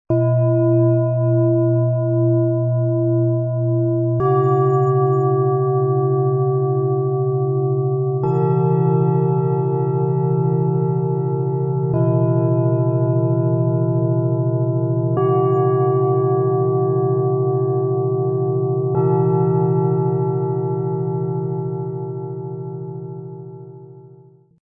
Energie aufbauen, Gefühle harmonisieren, Herz öffnen - Kinder und Jugendlichen Klangmassage-Set aus 3 Planetenschalen, Ø 16,6 -20,6 cm, 2,48 kg
Tiefster Ton: Stärke tanken mit dem Biorhythmus Körper
Mittlerer Ton: Gefühle harmonisieren mit dem Mond
Höchster Ton: Das Herz öffnen mit dem Hopi
Im Sound-Player - Jetzt reinhören können Sie den Original-Ton genau dieser Schalen, des Sets anhören. Lassen Sie sich von den sanften und harmonischen Klängen verzaubern, die besonders für Kinder und Jugendliche eine beruhigende Wirkung haben.
Industriell produzierte Ware könnte ein homogenes Aussehen versprechen, doch nur unsere traditionell gefertigten Schalen bieten eine tiefe Resonanz und eine unverwechselbare Klangqualität.